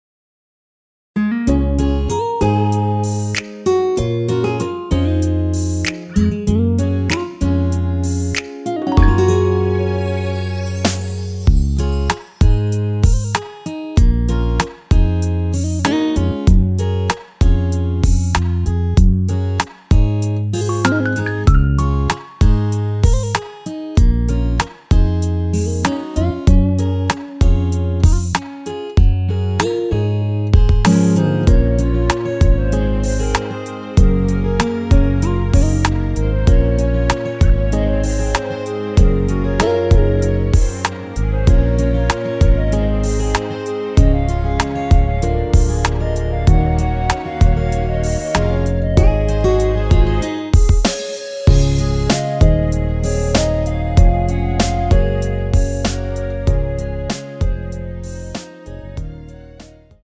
원키에서(-7)내린 멜로디 포함된 MR입니다.(미리듣기 확인)
Db
멜로디 MR이라고 합니다.
앞부분30초, 뒷부분30초씩 편집해서 올려 드리고 있습니다.